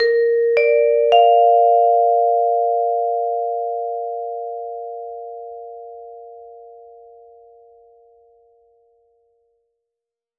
Tannoy.wav